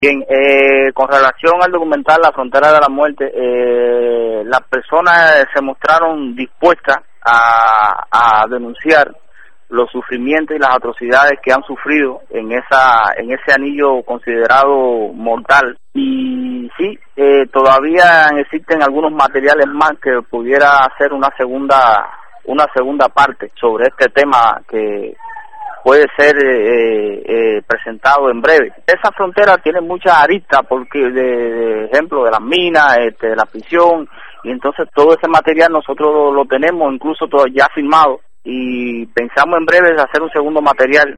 Declaraciones
desde Guantanamo, Cuba